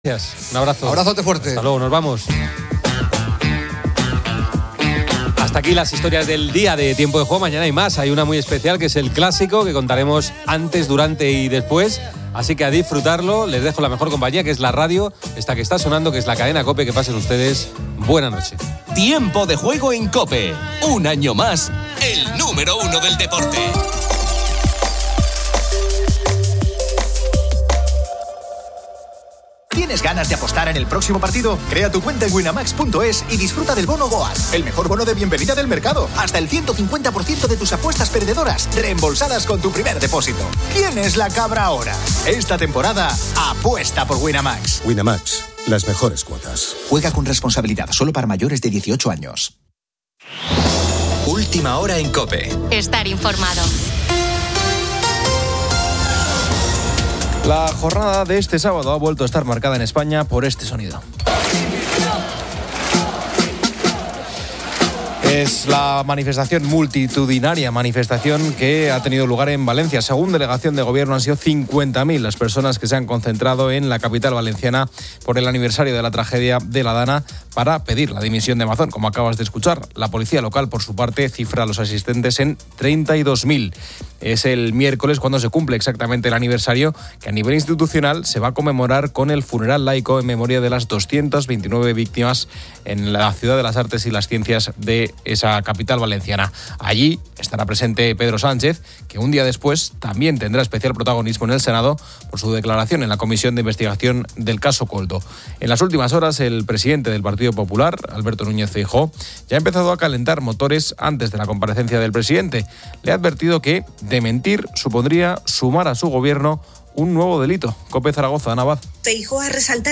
El programa finaliza con música y un brindis.